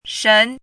shén
国际音标：ʂʅ˧˥;/ʂən˧˥
shén.mp3